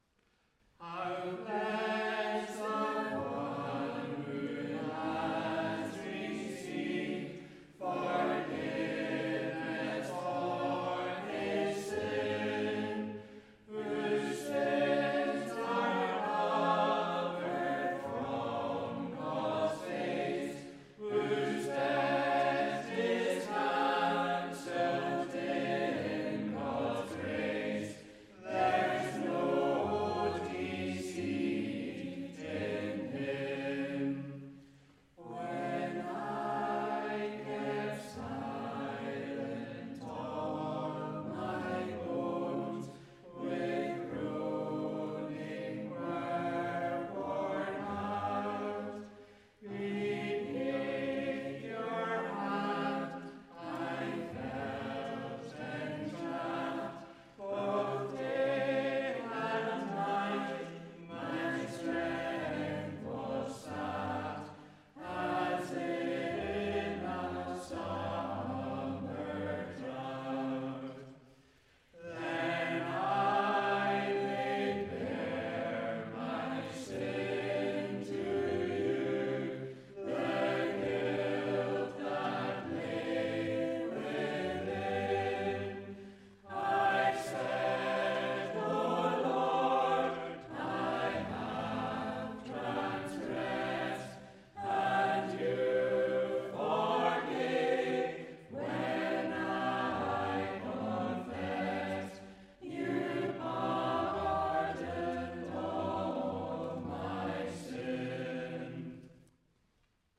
Psalm 32 (Evening Worship 16 July 2025)
Congregational Psalm Singing